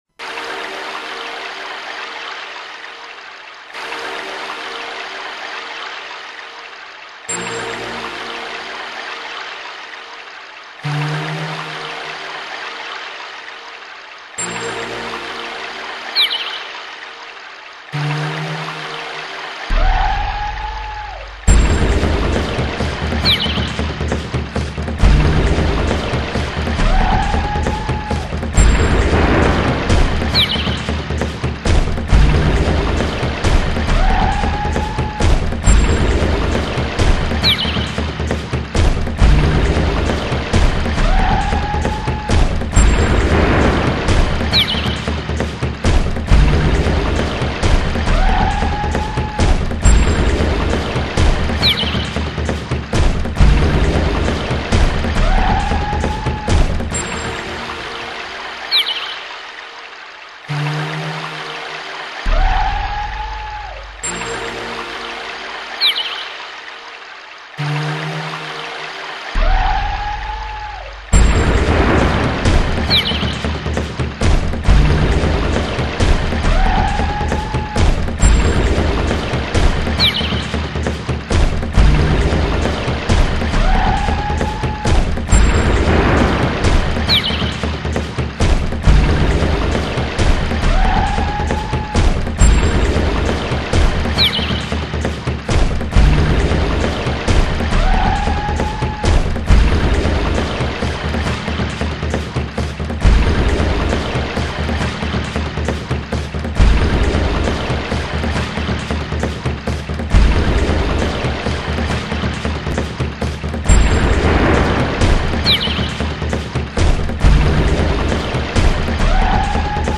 アンビエント 初めてシンセドラム（Roland SPD-20）で作ってみた環境音楽です。
PCの打ち込みによって効果音やフレーズを自動演奏しているので、